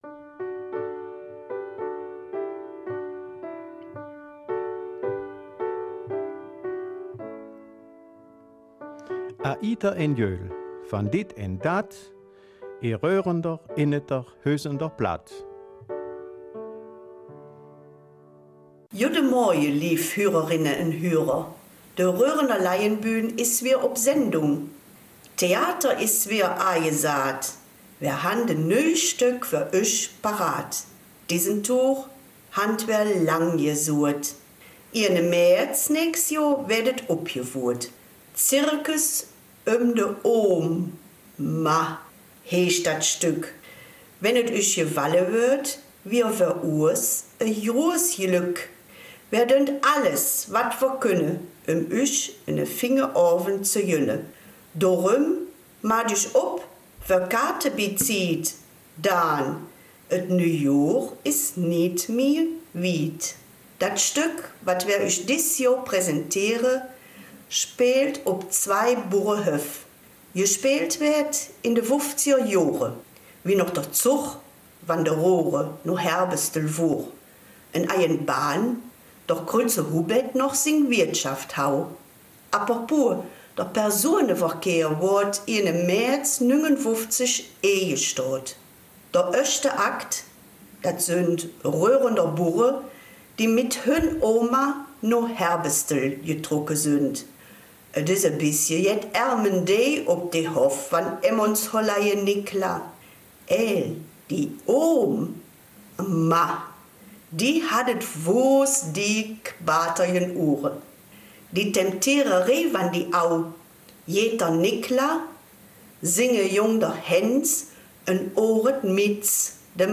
Die Raerener Mundartsendung am Sonntag, dem 29. Dezember, von 9 bis 10 Uhr und wiederholt ab 19 Uhr auf BRF2.